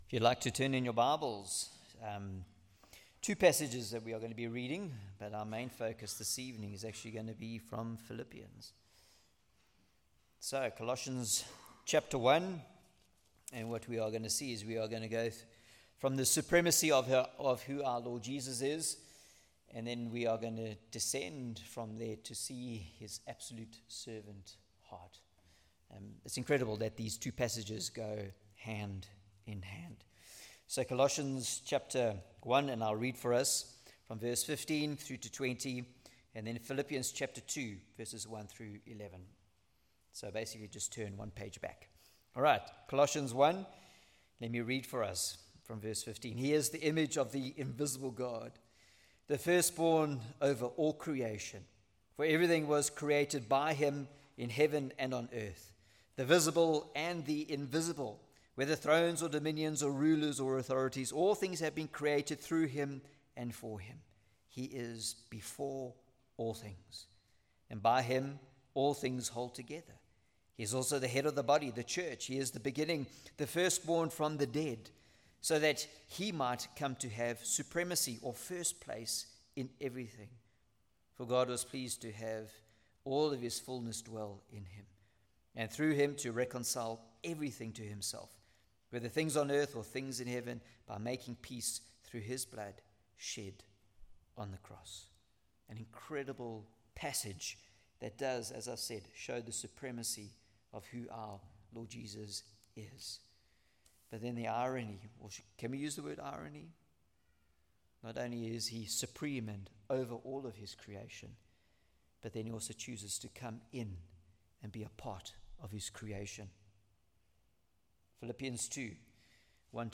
Colossians Passage: Colossians 1:15-20, Philippians 2:1-11 Service Type: Sunday Evening